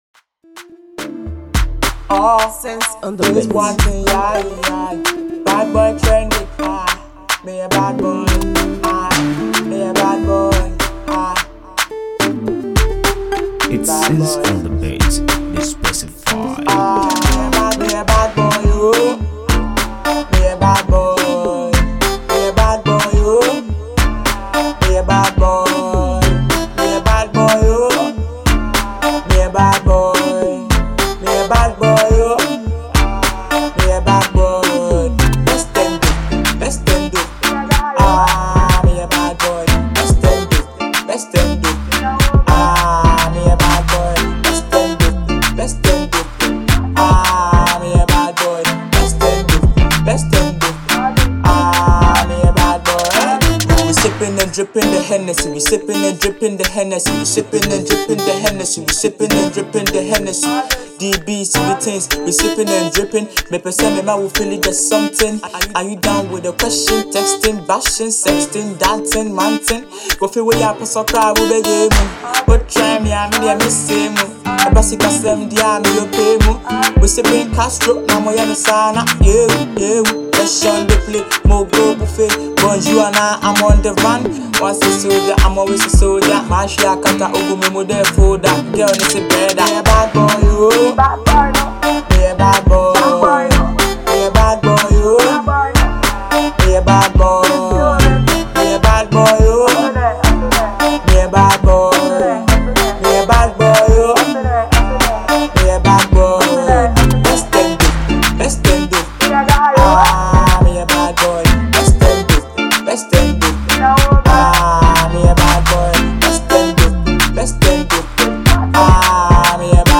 dope mid tempo banger (107)